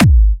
VEC3 Bassdrums Trance 05.wav